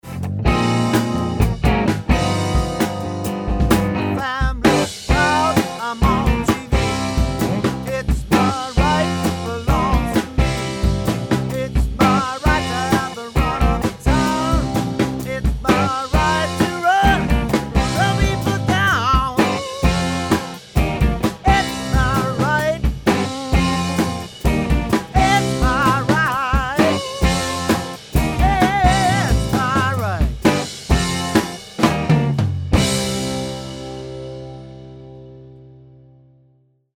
Caution: Loud
Mixed & Mastered
Mixed (No Mastering)
Before / Raw Tracks